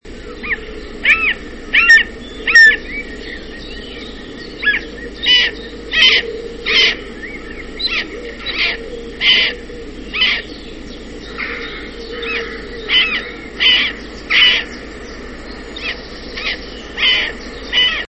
Myszołów - Buteo buteo